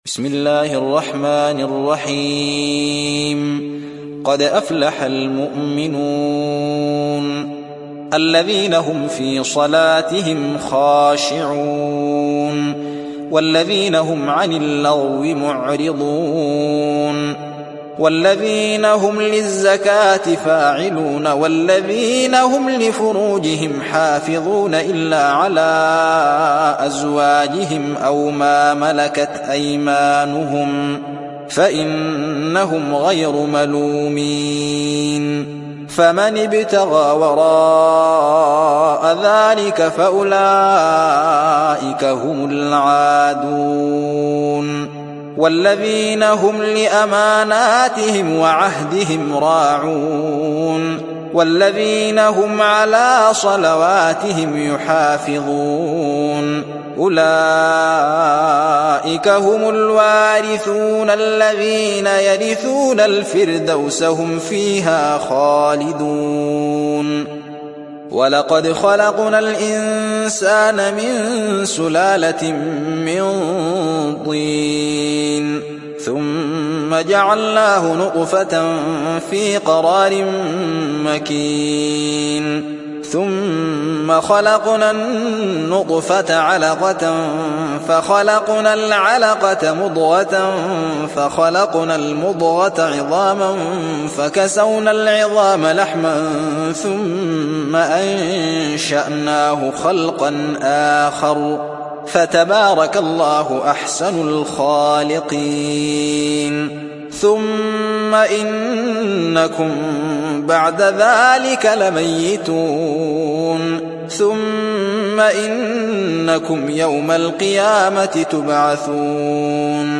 تحميل سورة المؤمنون mp3 بصوت الزين محمد أحمد برواية حفص عن عاصم, تحميل استماع القرآن الكريم على الجوال mp3 كاملا بروابط مباشرة وسريعة